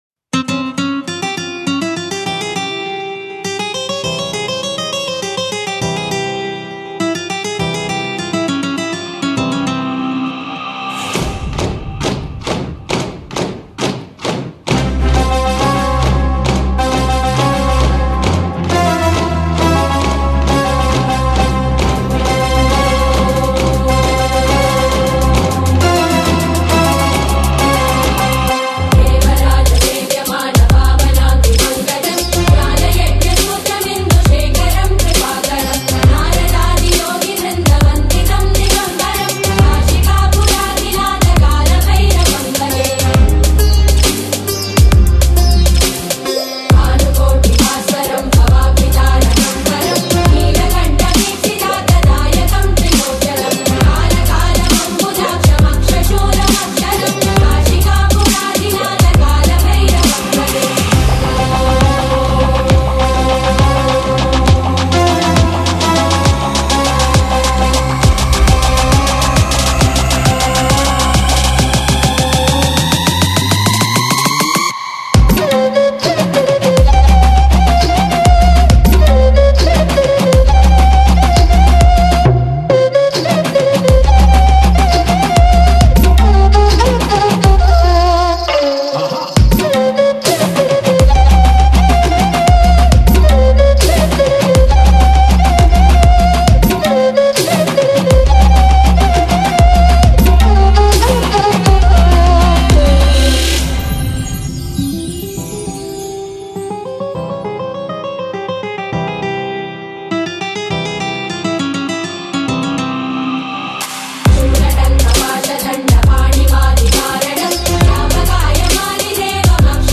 DJ Remix Mp3 Songs
Spiritual Dance Music